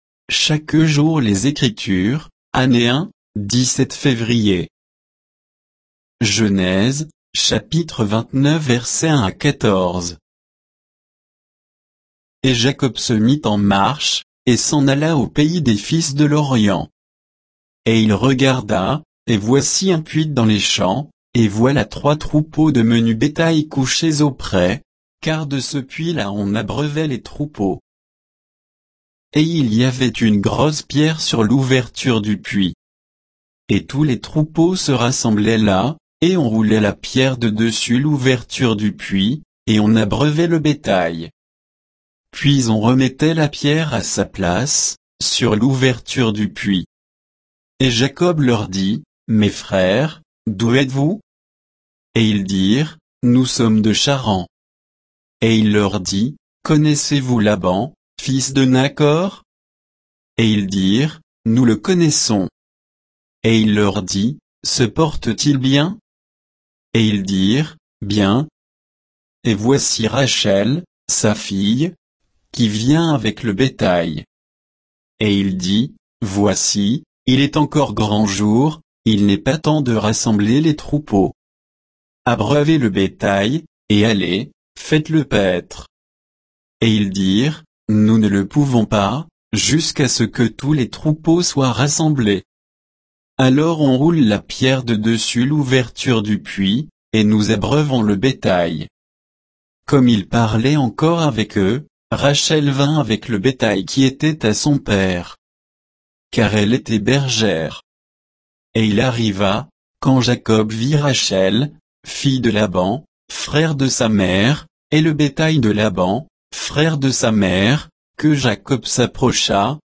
Méditation quoditienne de Chaque jour les Écritures sur Genèse 29